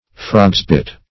Frogs-bit \Frog"s`-bit"\, n. (Bot.)